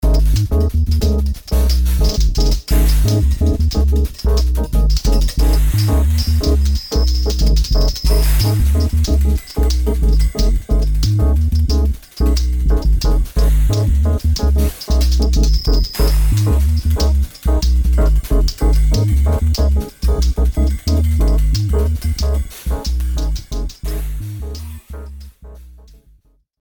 Drum Tracking (Creative)
Crushed-Drums.mp3